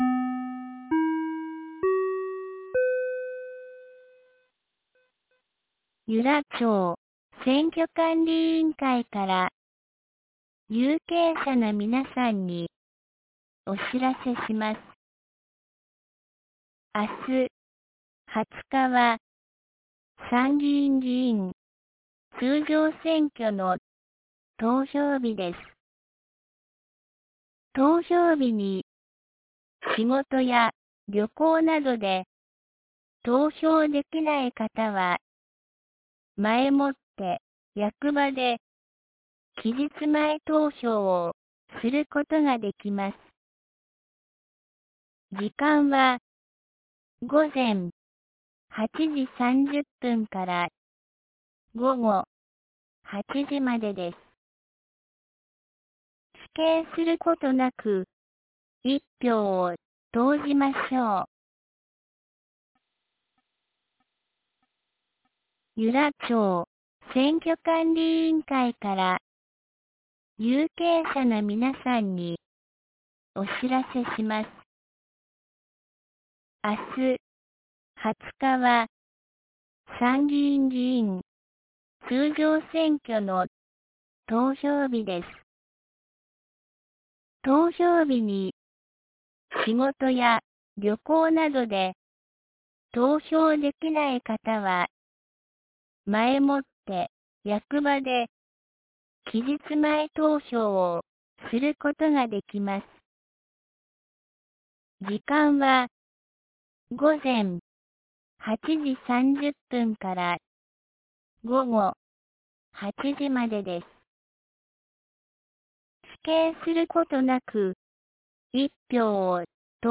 2025年07月19日 17時12分に、由良町から全地区へ放送がありました。